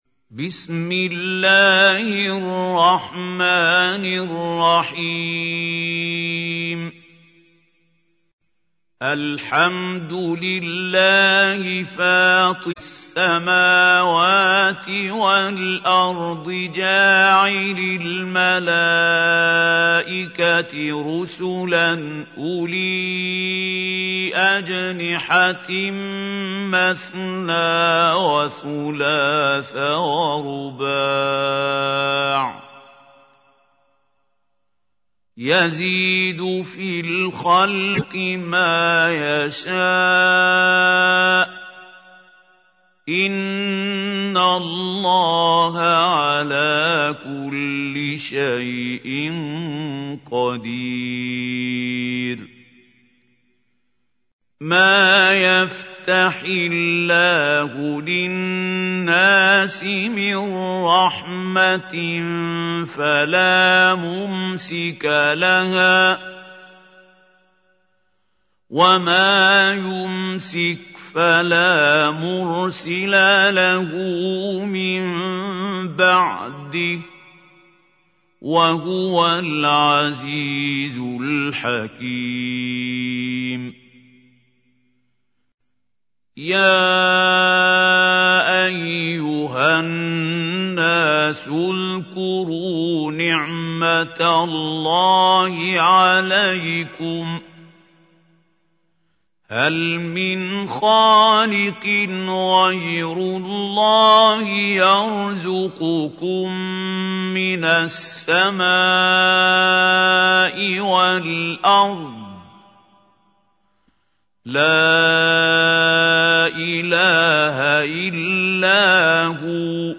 تحميل : 35. سورة فاطر / القارئ محمود خليل الحصري / القرآن الكريم / موقع يا حسين